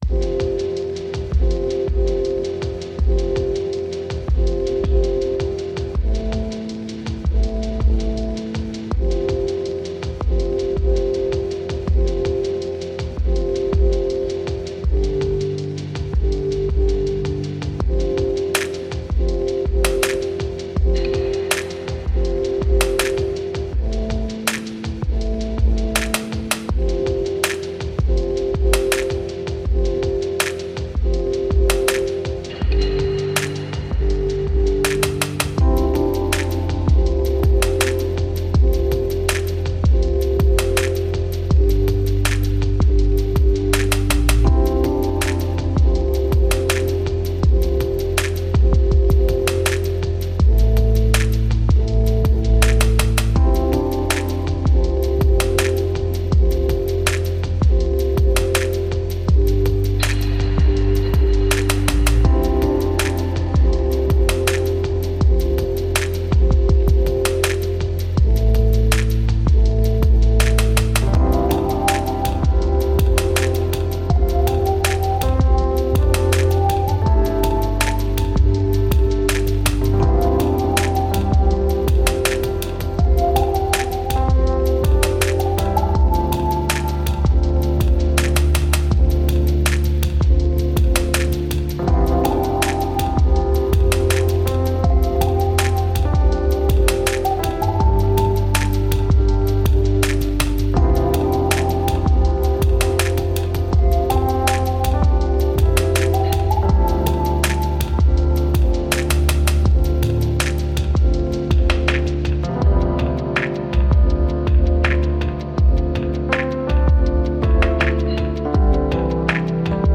Intriguing ambient music telling tales from south africa.